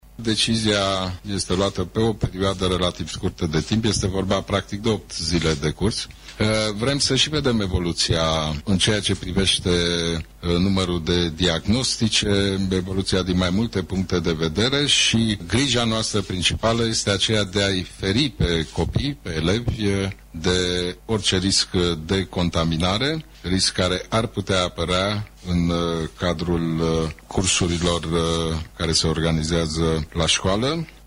Premierul Ludovic Orban a explicat că măsura se aplică de miercuri, pentru doar 8  zile de curs și în funcție de evoluția îmbolnăvirilor există posibilitatea de prelungire a acestei măsuri: